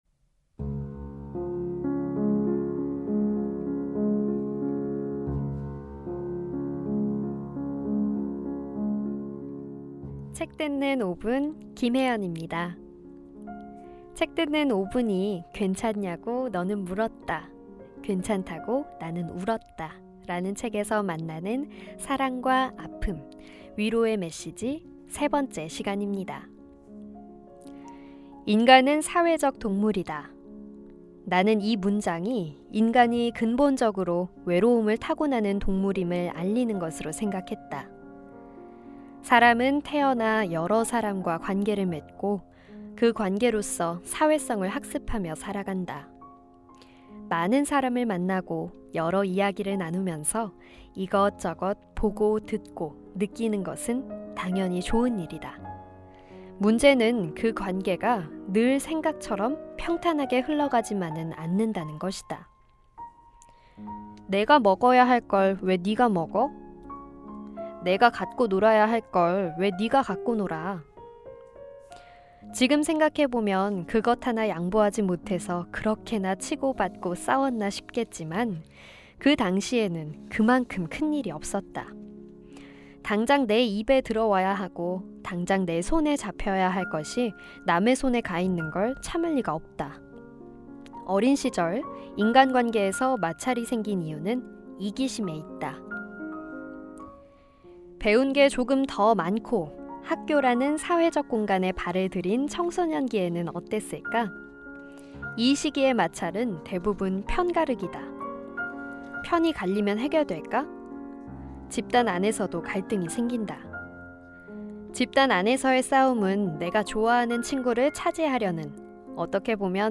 북 큐레이터